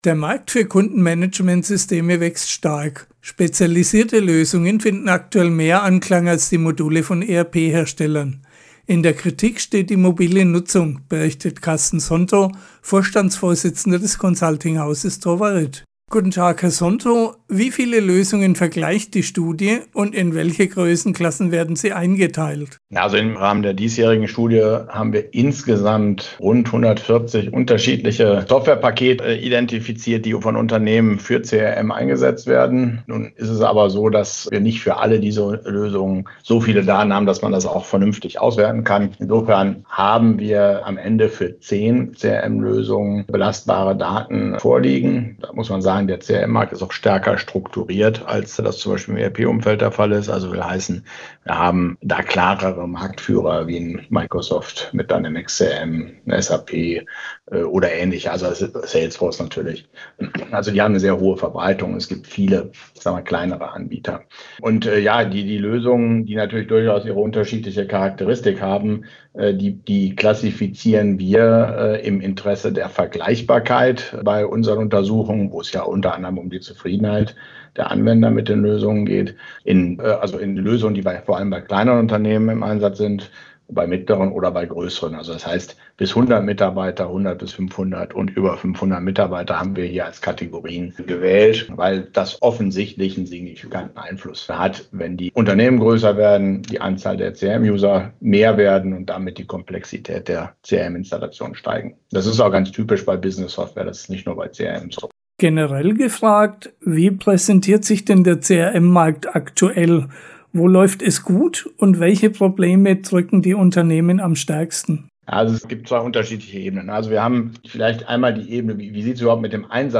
Interview CRM in der Praxis 2021 - Trovarit AG - Software & Prozesse richtig verzahnen
interview-crm-praxis-2021.mp3